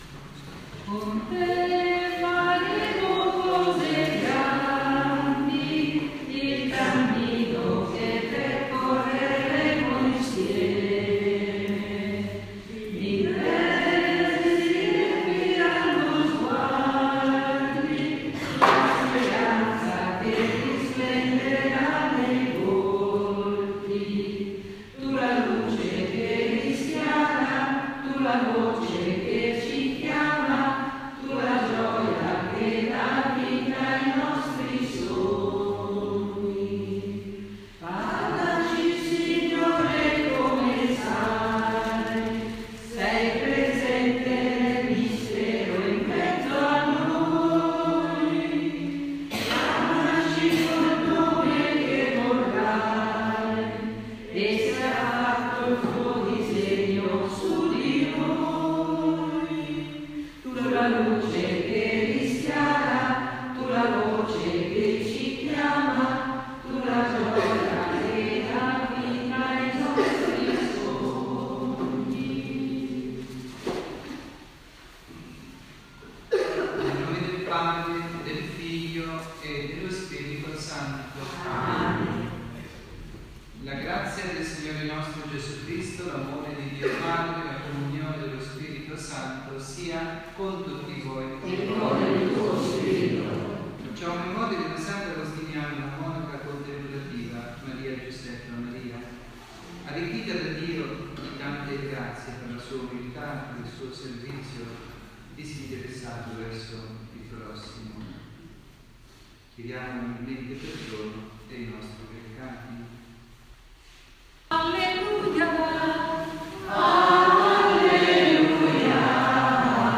Incontro di preghiera e Catechesi
dalla Parrocchia S. Rita, Milano